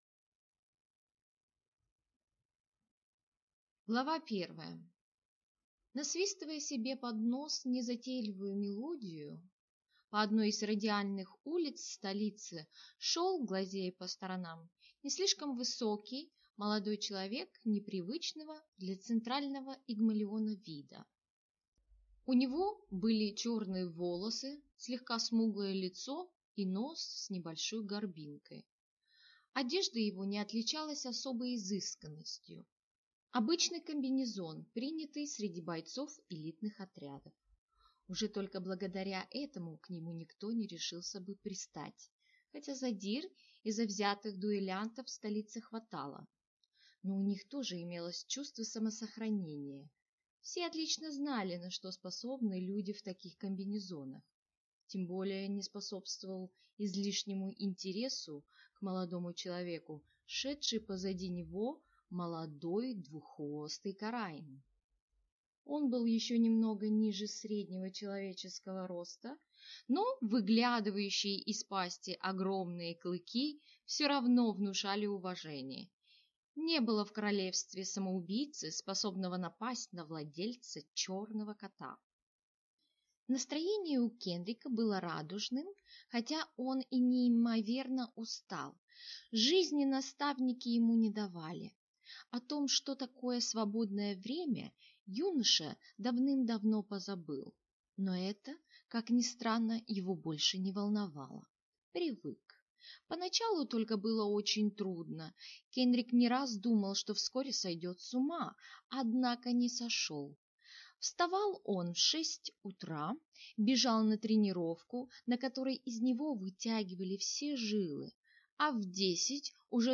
Аудиокнига Витой Посох. Постижение | Библиотека аудиокниг